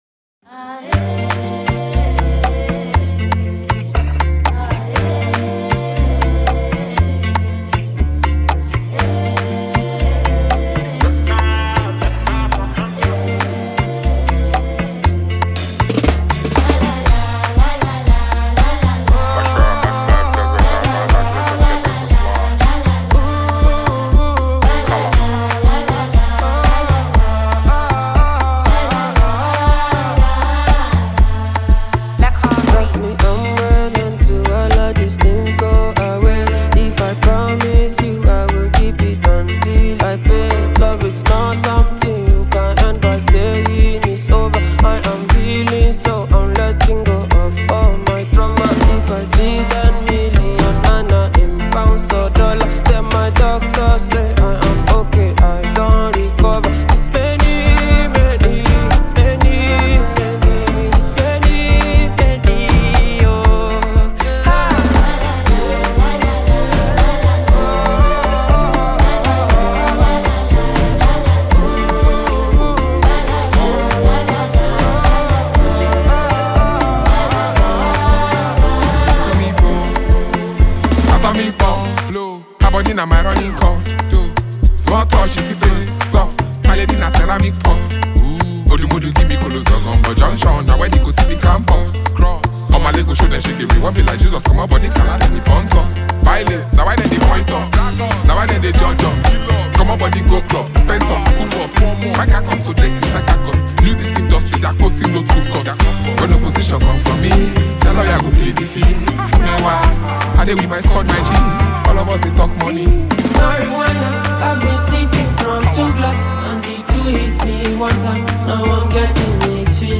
” blends playful rhythms with catchy hooks